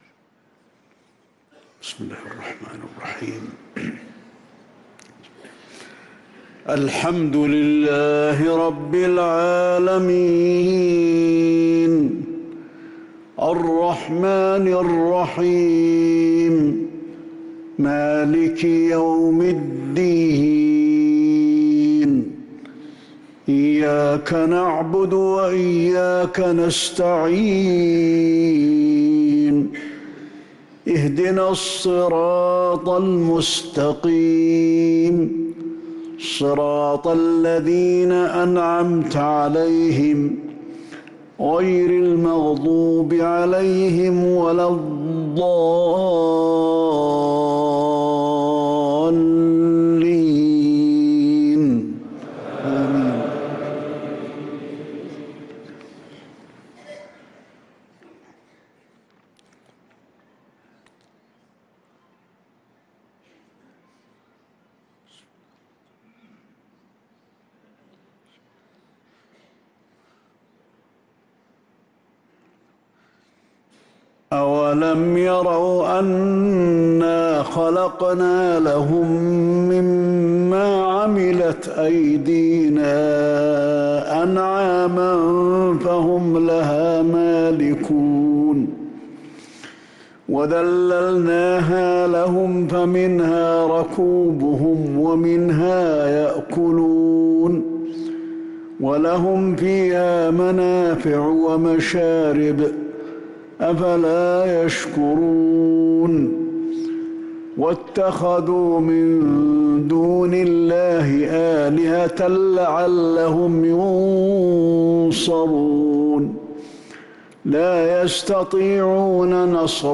صلاة العشاء للقارئ علي الحذيفي 7 جمادي الآخر 1445 هـ
تِلَاوَات الْحَرَمَيْن .